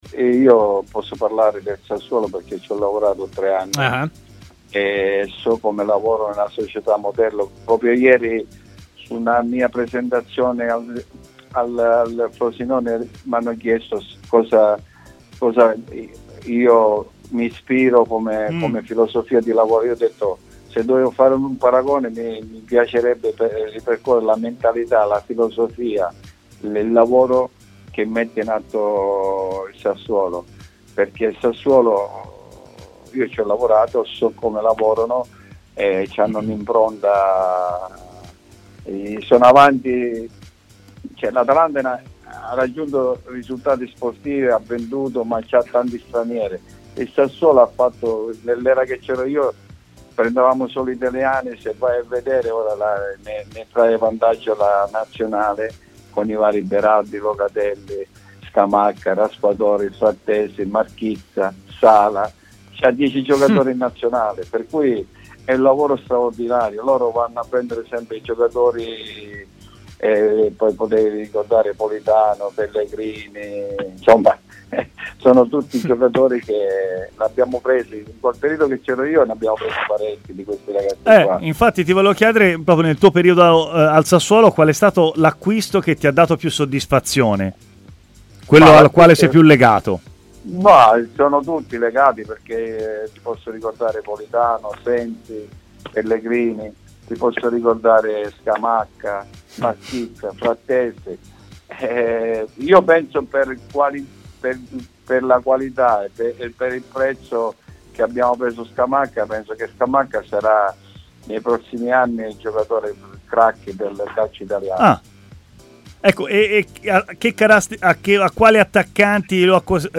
è intervenuto in diretta a Stadio Aperto, trasmissione di TMW Radio